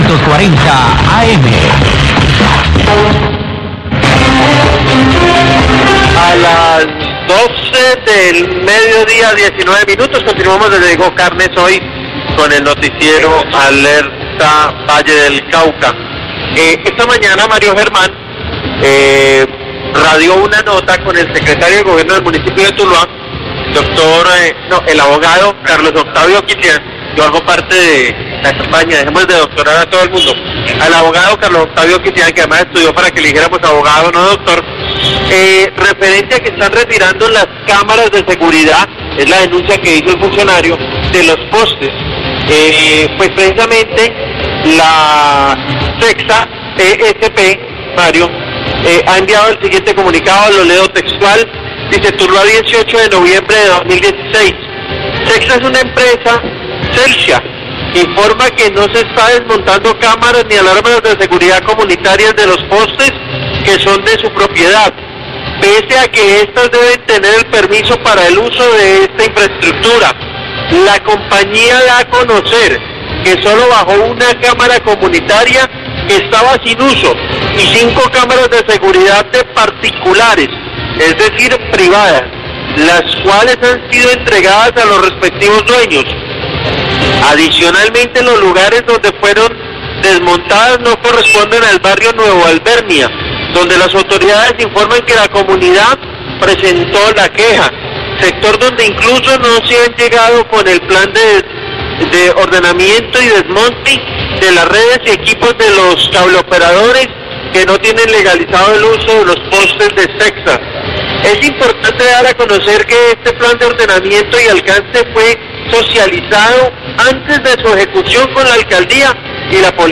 Radio
Leen al aire el comunicado enviado por CETSA donde informa que no se están desmontando cámaras ni alarmas comunitarias de los postes, pero señala los casos en los que sí se hizo desmonte de cámaras que no se encuentran en funcionamiento entre otros motivos.